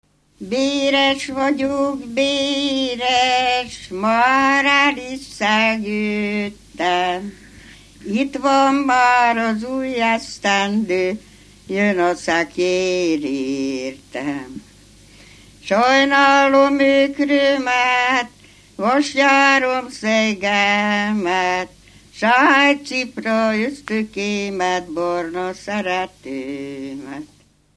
Dunántúl - Fejér vm. - Soponya
ének
Stílus: 5. Rákóczi dallamkör és fríg környezete
Kadencia: 4 (1) 1 V